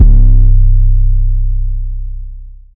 without warning 21 808.wav